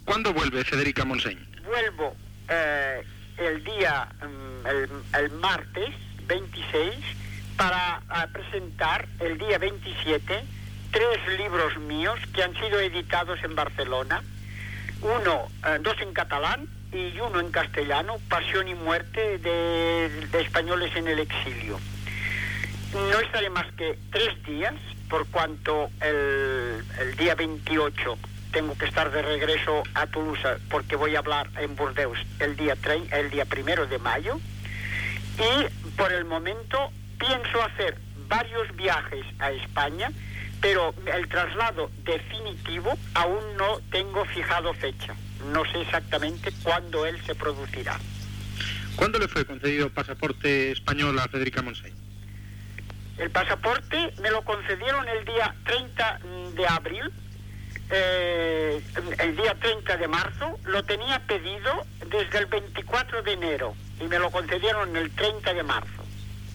Entrevista a la política Federica Montseny que anuncia la seva anada a Barcelona per presentar uns llibres, tot i que encara no sap quan serà el seu retorn definitiu de l'exili
Informatiu